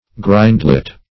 grindlet - definition of grindlet - synonyms, pronunciation, spelling from Free Dictionary Search Result for " grindlet" : The Collaborative International Dictionary of English v.0.48: Grindlet \Grind"let\, n. A small drain.